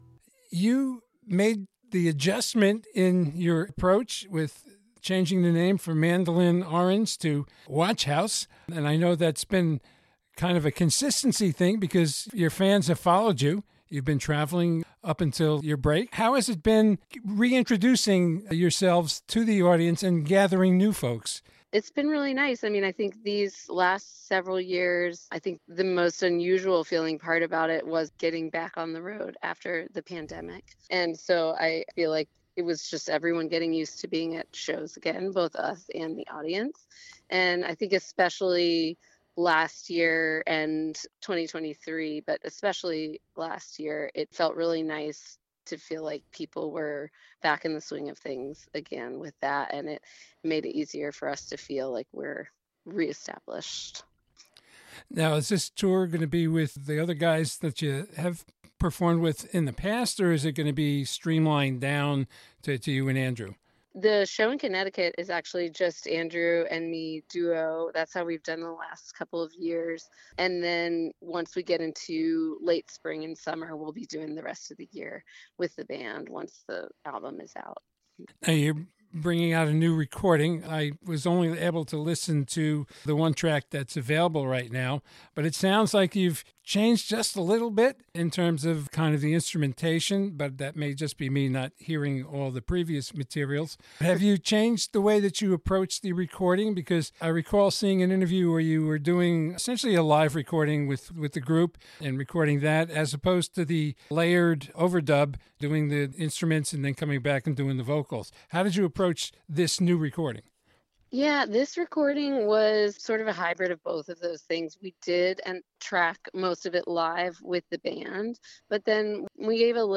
WATCHHOUSE-INTERVIEW-EDIT-1.mp3